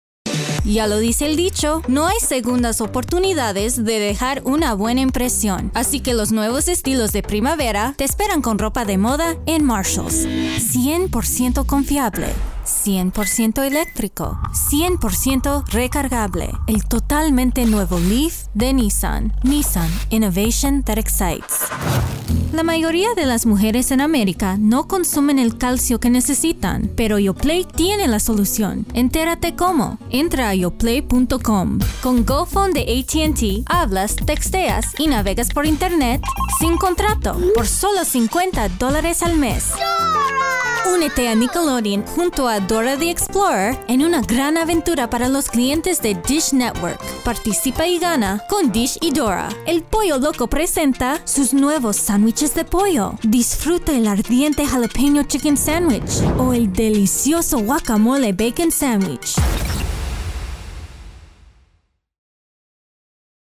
Award Winning Voice Actress
I have a broadcast quality home studio with Source Connect, Skype, and Zoom capabilities.